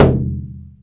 BODHRAN 2A.WAV